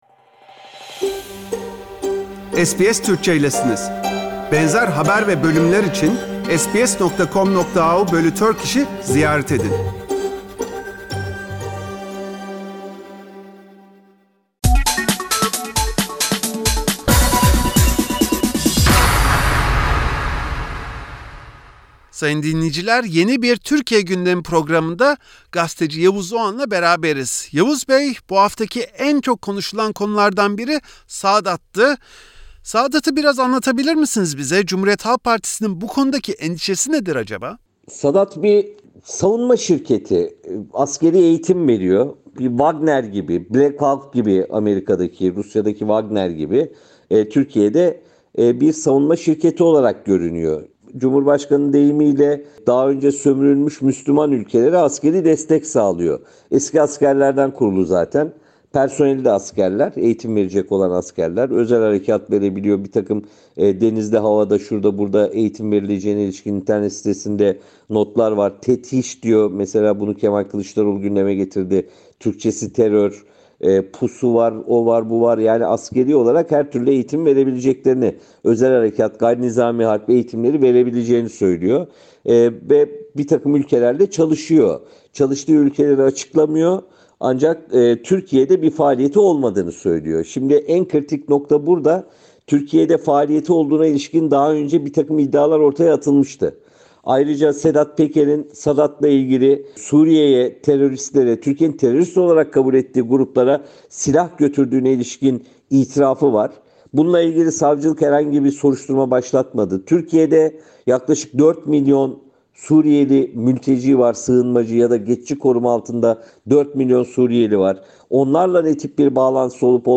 Gazeteci Yavuz Oğhan gündemi meşgul eden konular arasında millet bahçesi olarak Atatürk Havalimanı ve hakkında çok az şey bilinen Sadat hakkındaki sorularımızı cevaplandırdı.